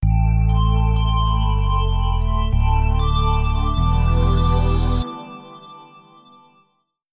Windows NT 6.1 Startup (Remastered).wav